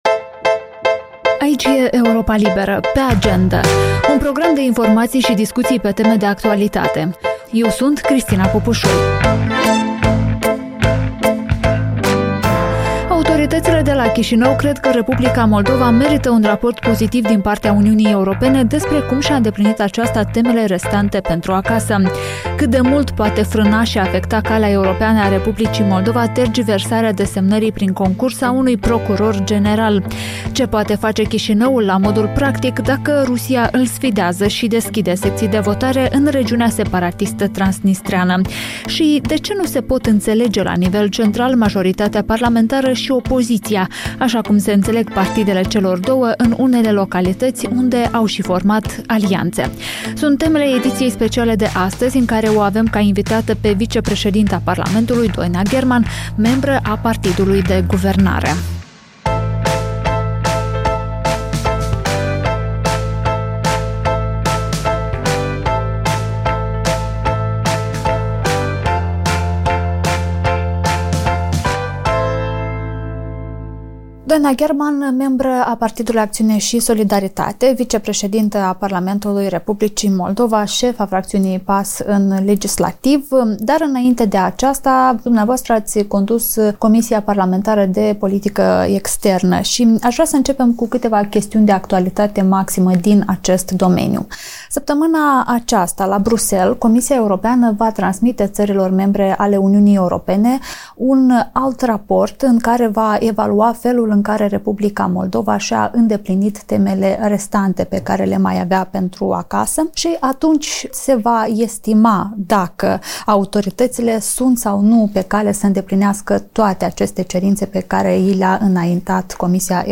Vicepreședinta Parlamentului, Doina Gherman, vorbește în podcastul video „Pe Agendă” de la Europa Liberă despre felul în care concursul eșuat pentru funcția de procuror general al R. Moldova ar aduce atingere procesului de integrare europeană.